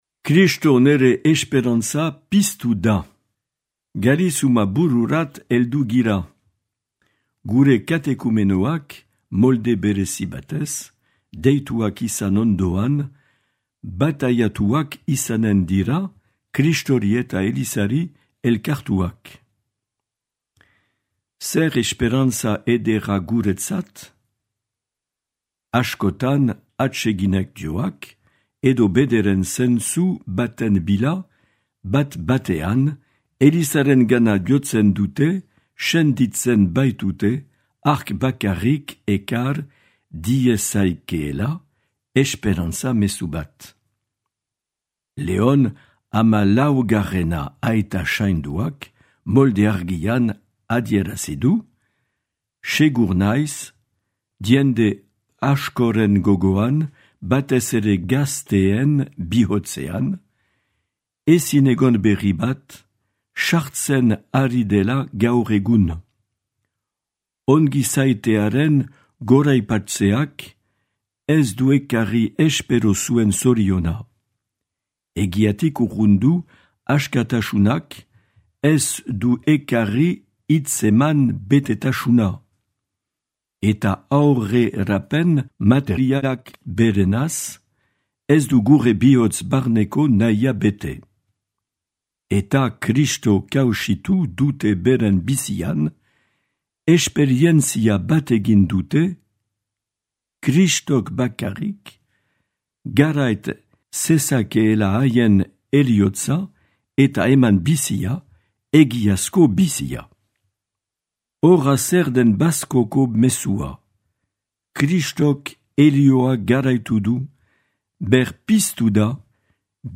Bazko mezua 2026 - Marc Aillet, Baionako apezpikua